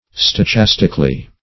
stochastically - definition of stochastically - synonyms, pronunciation, spelling from Free Dictionary